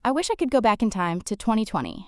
「could go」（クッド・ゴー）は、「クッゴー」